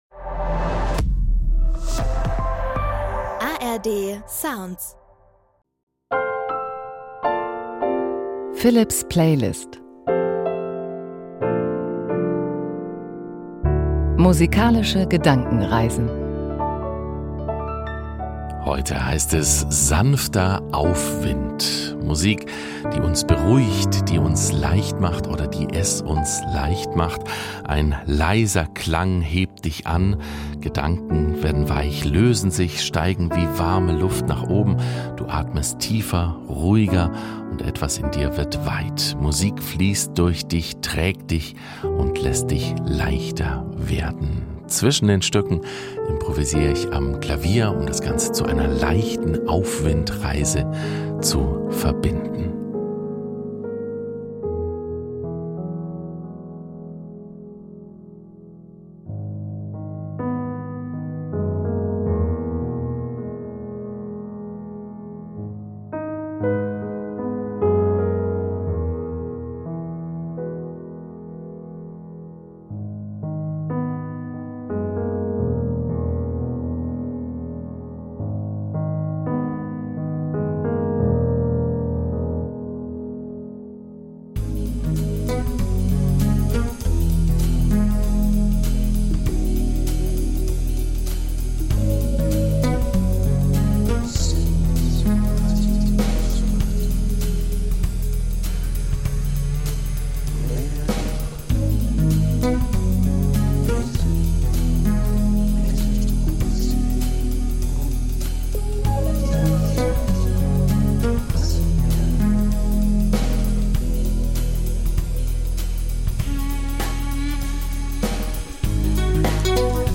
Weiche Klänge, die beruhigen und Leichtigkeit schenken.